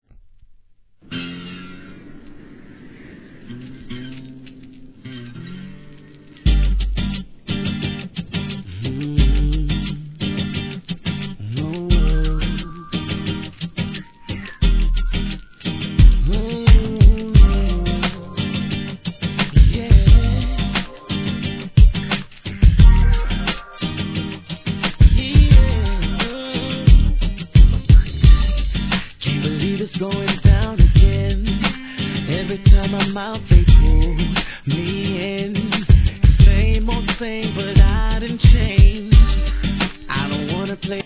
G-RAP/WEST COAST/SOUTH
弾むギターの上音が心地よいトラックにヴォコーダを微妙に使う所が実ににくいR&B。